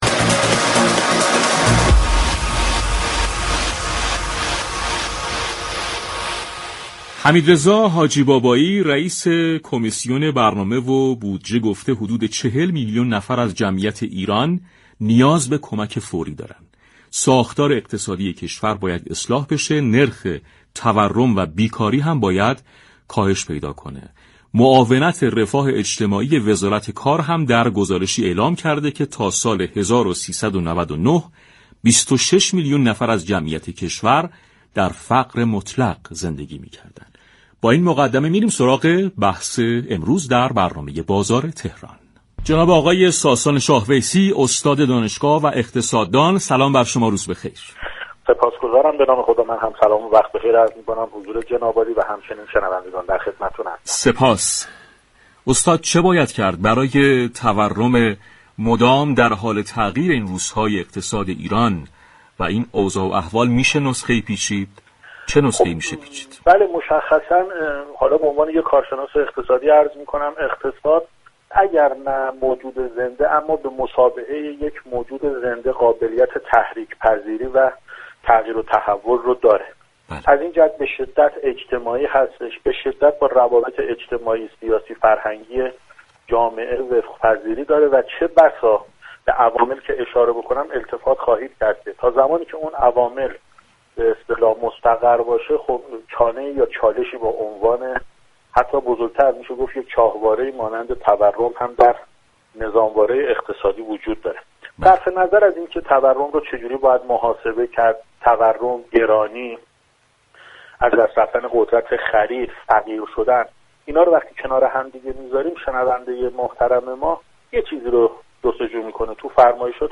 در گفتگو باسعادت آباد رادیو تهران